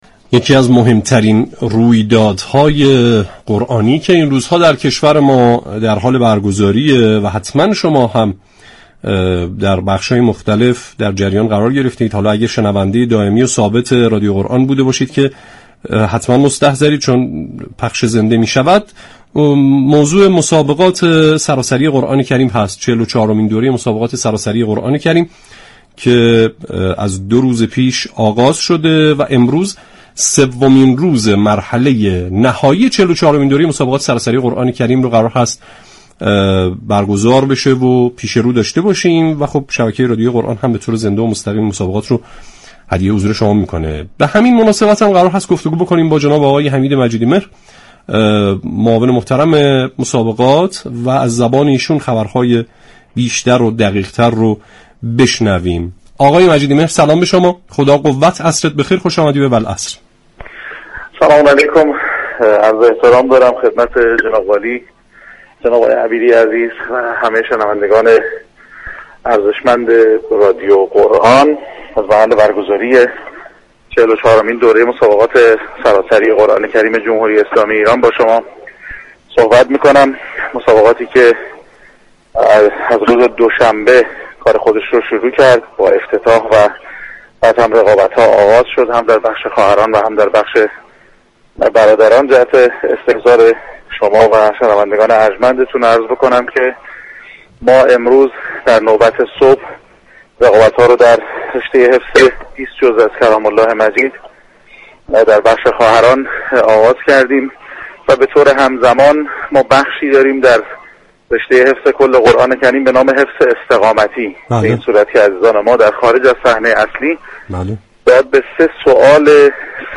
در گفتگو با برنامه والعصر رادیو قرآن